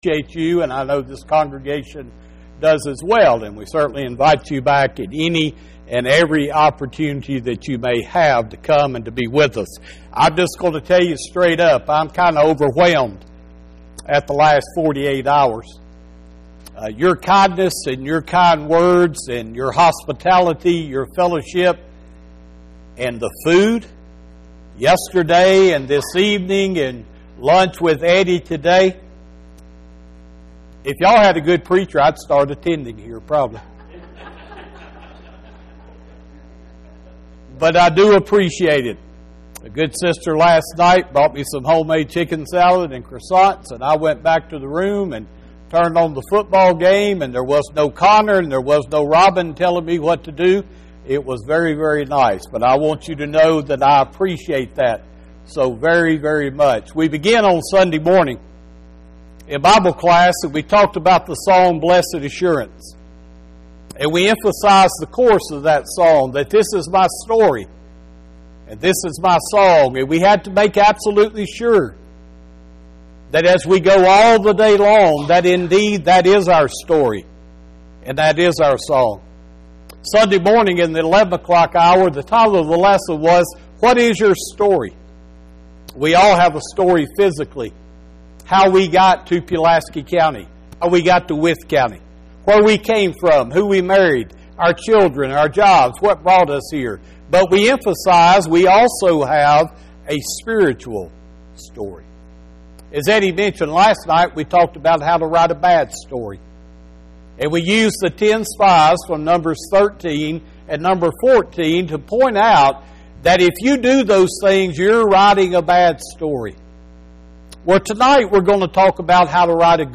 10-27-25 Guidelines for a Good Story – Gospel Meeting